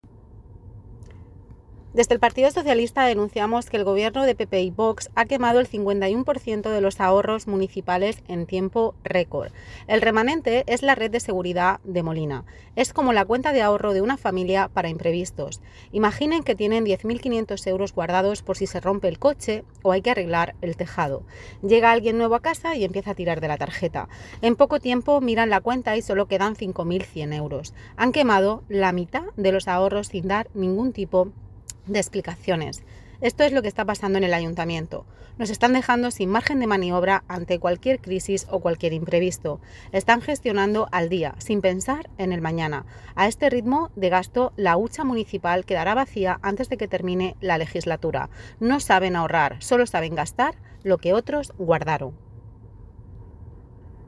La portavoz socialista, Isabel Gadea, ha utilizado un ejemplo cotidiano para explicar qué significa esta pérdida de remanente para los vecinos: